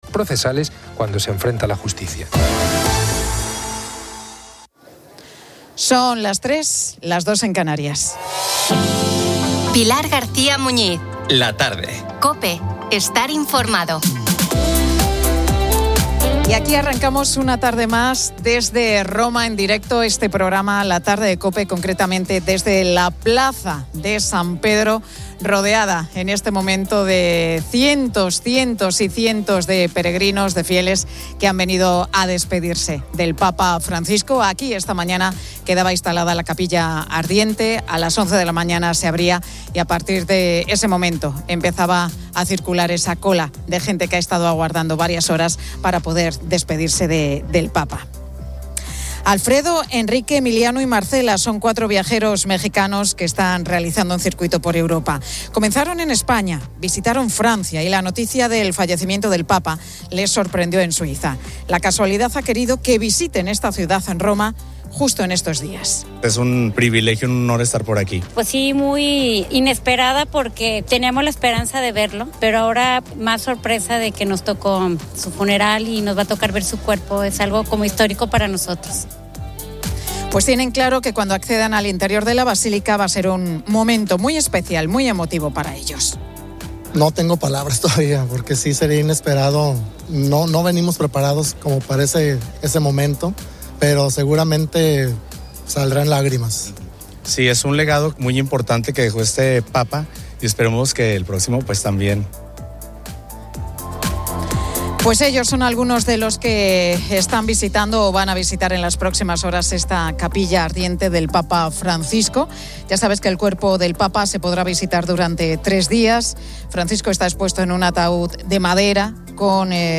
La Tarde 15:00H | 23 ABR 2025 | La Tarde La Tarde de COPE con Pilar García Muñiz desde Roma en el primer día de capilla ardiente para despedir al Papa Francisco.